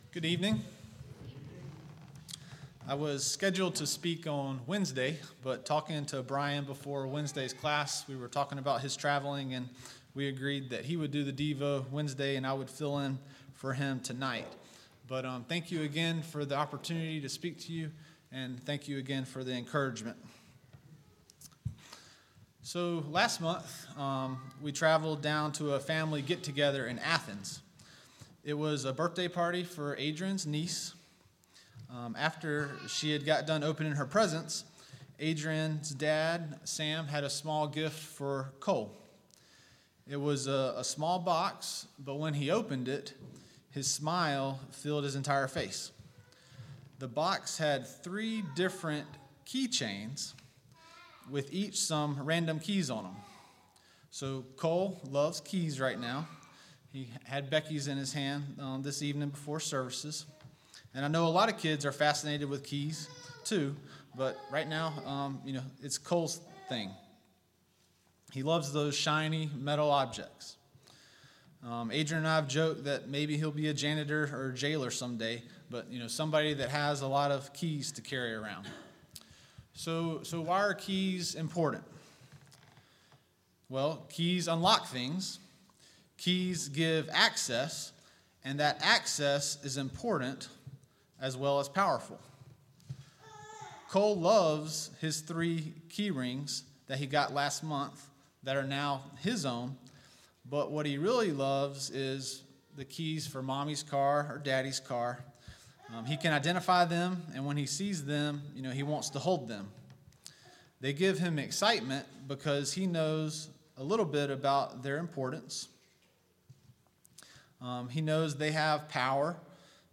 Passage: Matthew 16:16-20 Service Type: PM Worship Bible Text